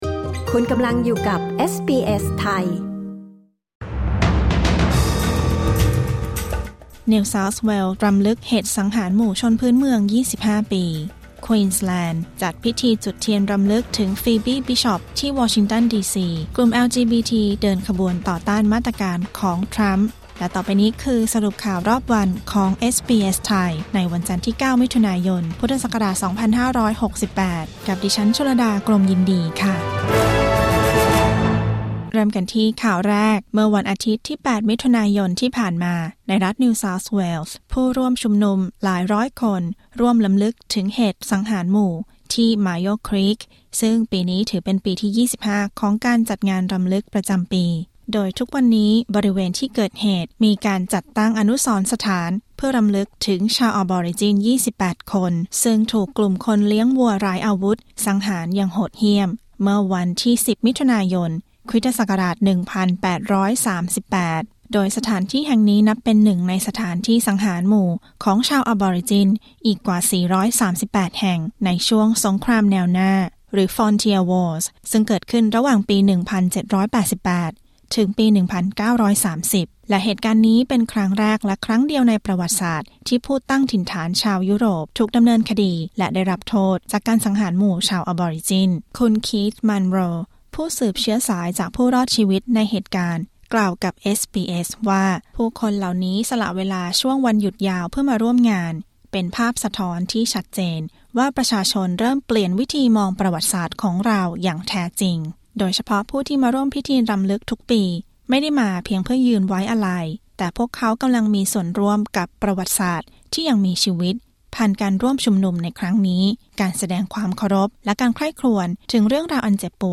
สรุปข่าวรอบวัน 9 มิถุนายน 2568